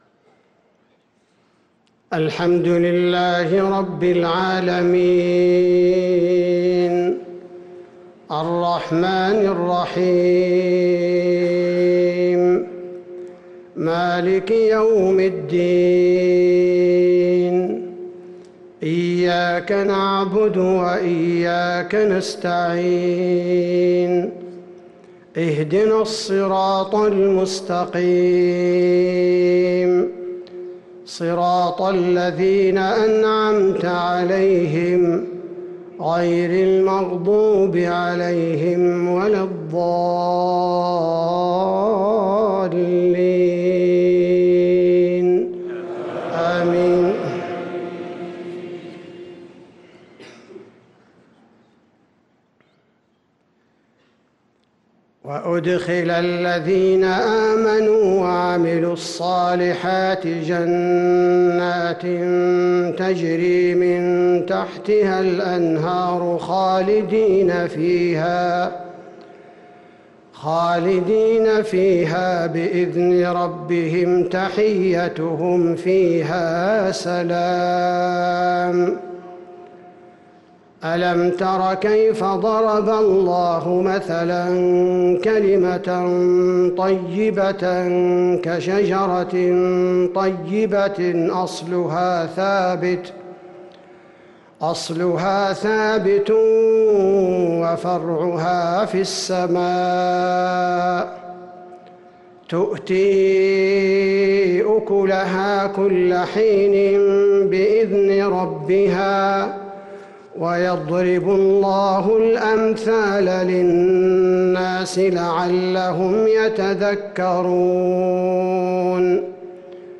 صلاة المغرب للقارئ عبدالباري الثبيتي 30 شوال 1444 هـ
تِلَاوَات الْحَرَمَيْن .